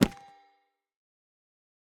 Minecraft Version Minecraft Version 25w18a Latest Release | Latest Snapshot 25w18a / assets / minecraft / sounds / block / chiseled_bookshelf / pickup_enchanted3.ogg Compare With Compare With Latest Release | Latest Snapshot
pickup_enchanted3.ogg